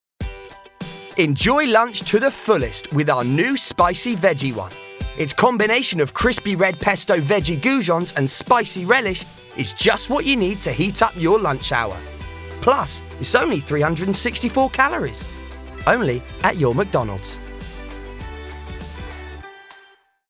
Tyger has a neutral RP accent with an upbeat, youthful and enthusiastic tone to his voice.
• Male